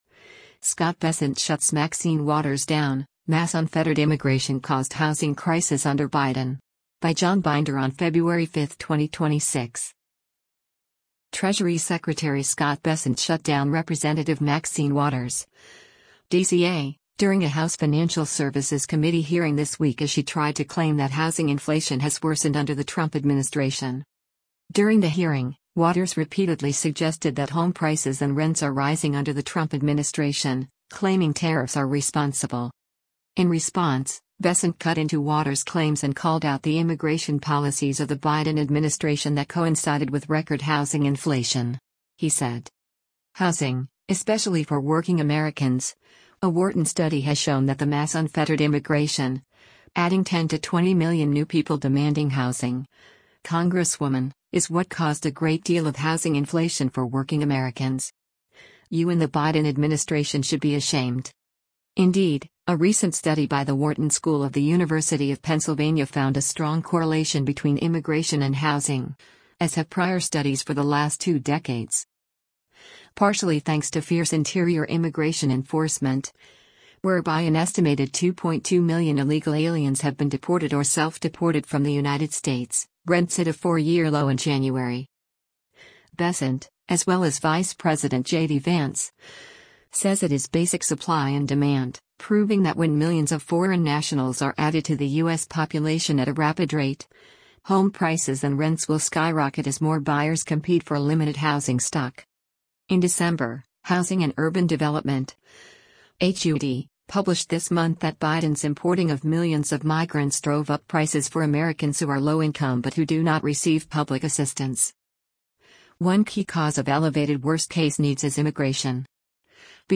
Treasury Secretary Scott Bessent shut down Rep. Maxine Waters (D-CA) during a House Financial Services Committee hearing this week as she tried to claim that housing inflation has worsened under the Trump administration.